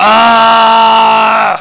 voice_battlecry.wav